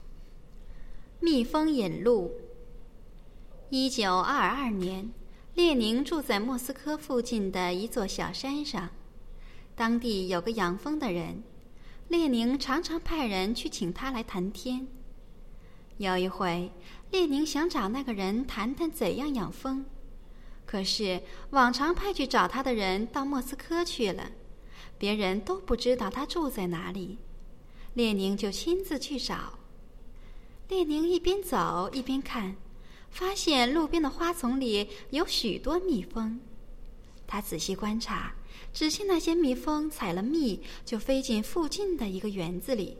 蜜蜂引路 课文及生字朗读